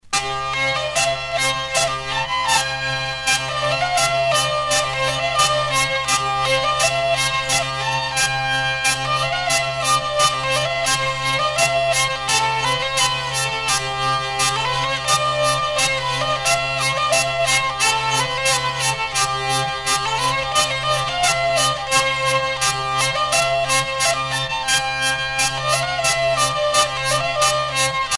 Chants brefs - Conscription
Pièce musicale éditée